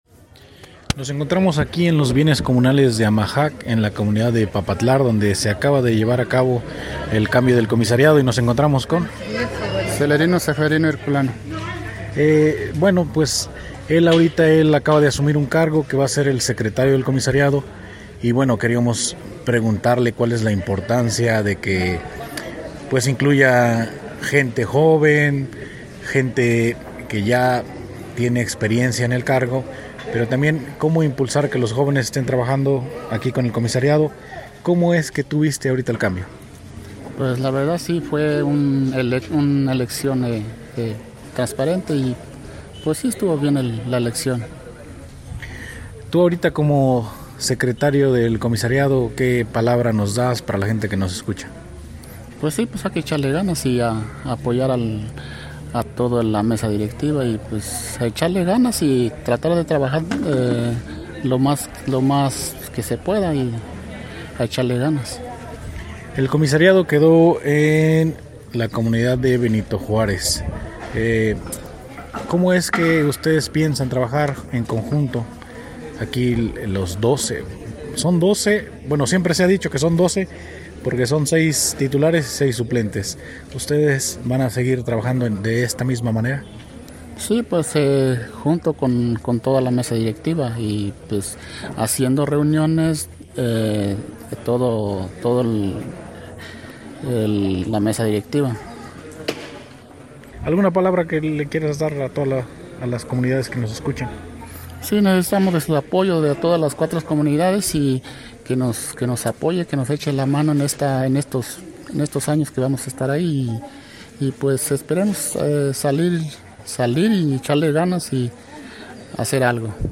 Desde el Papatlar en Texcatepec